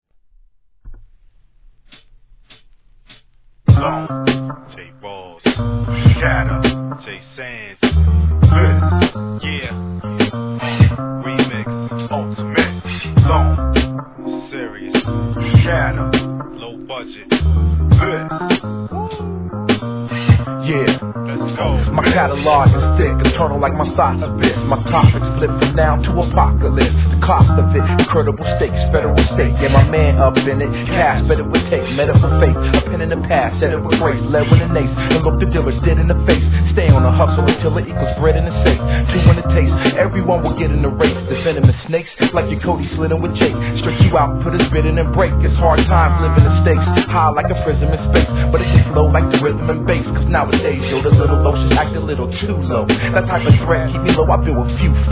HIP HOP/R&B
スパニッシュなサンプリングに切ないギターの流れるようなメロディ〜がメロウな雰囲気の